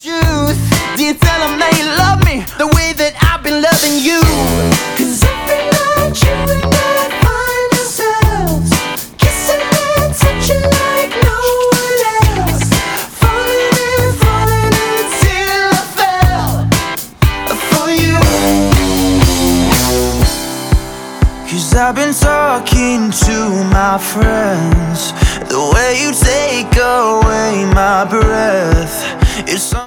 • Soundtrack
pop rock band